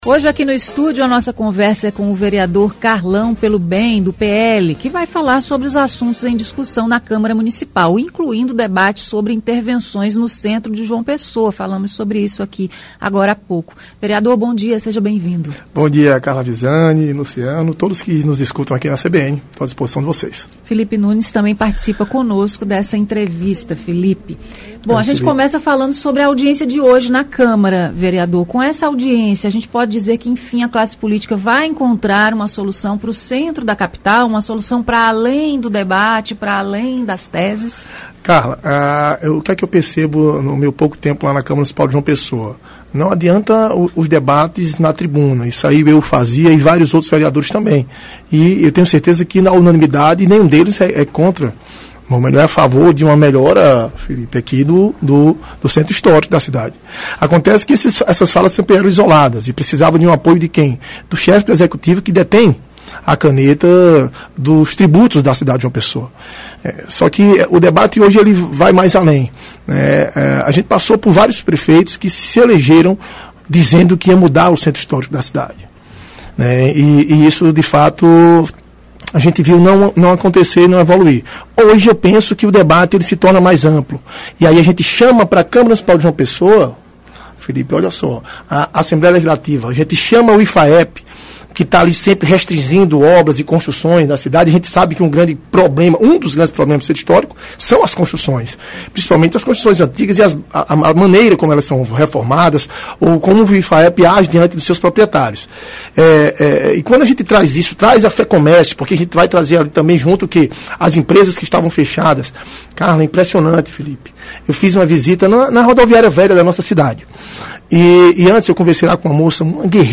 Entrevista: vereador Carlão Pelo Bem fala sobre intervenções no Centro de João Pessoa – CBN Paraíba
O vereador de João Pessoa, Carlão Pelo Bem, do PL, foi entrevistado nesta quarta-feira (18). Ele falou sobre os assuntos em discussão na Câmara Municipal, incluindo o debate sobre intervenções no Centro de João Pessoa.